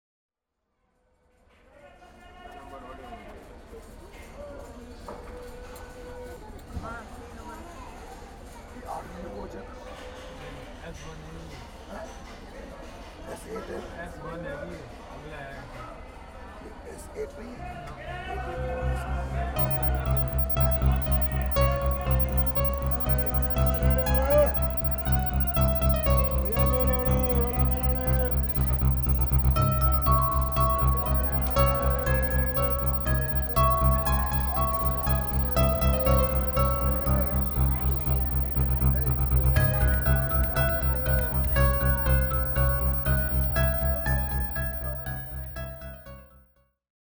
活性・エナジャイズ　収録時間: 52:15 min,
光る音楽（オーディオストロボ対応音源）